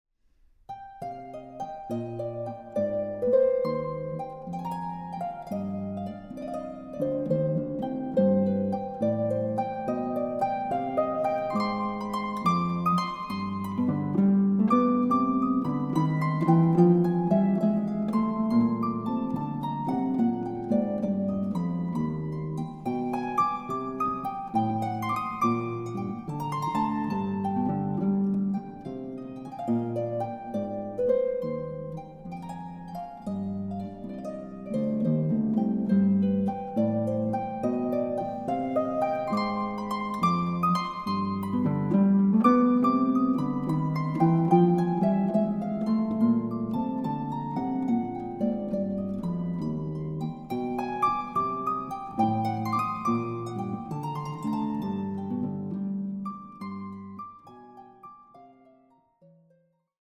Harp
Recording: Festeburgkirche Frankfurt, 2024